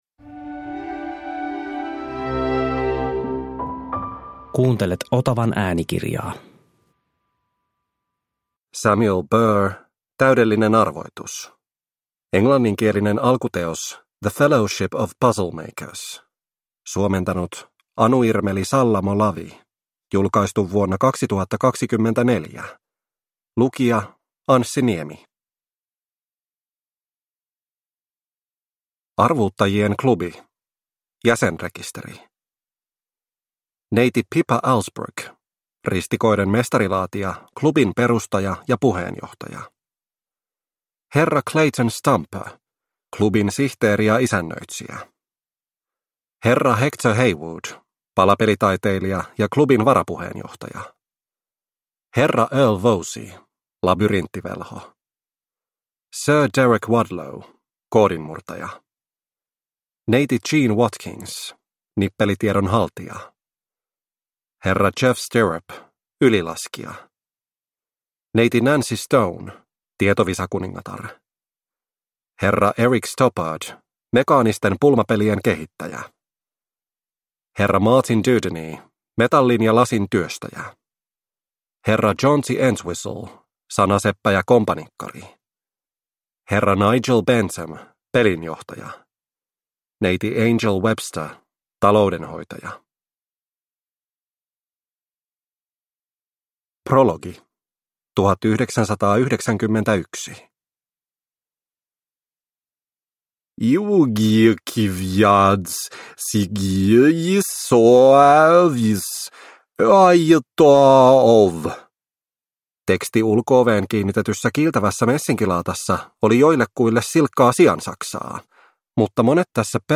Täydellinen arvoitus (ljudbok) av Samuel Burr